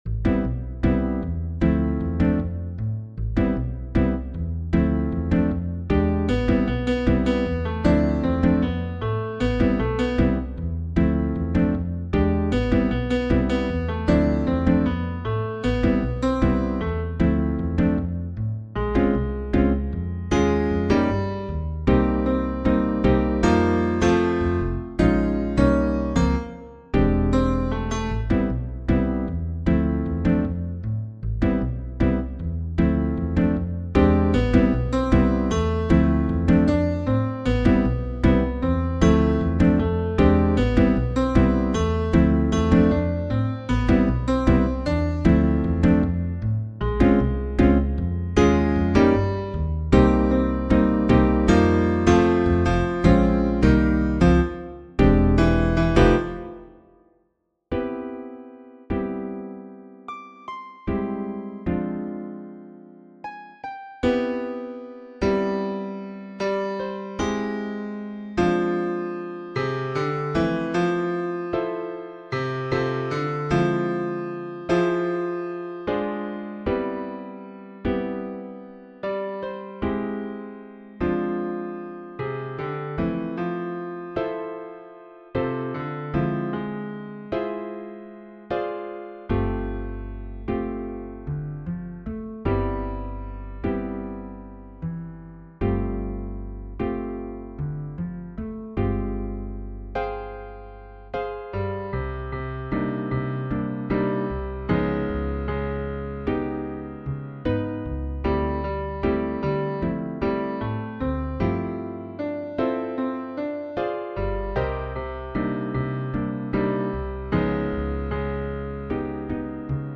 jazz_mass_gloria-bass-1.mp3